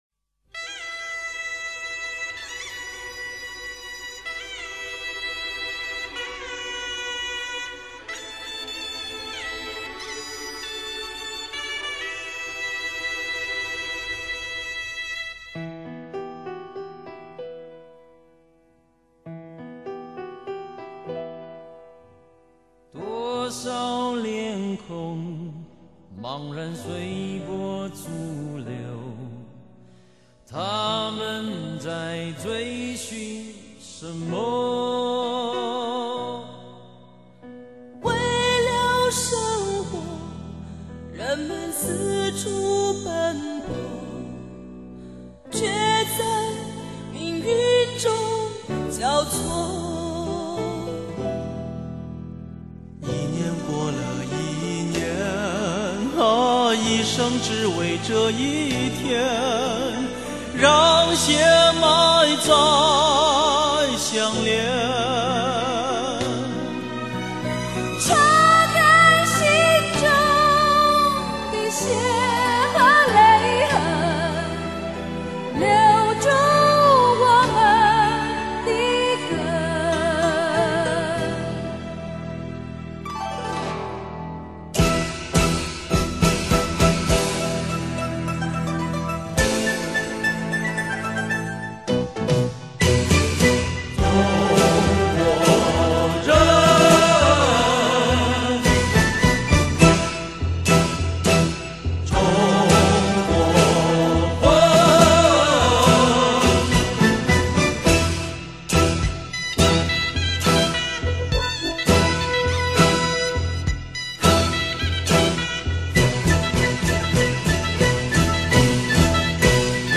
这首歌的最早版本是来自内地香港台湾三地明星的合唱。
歌曲间奏有“中国人，中国魂”的词句，并运用了中国传统的乐器---锁呐，具有浓郁的民族风味。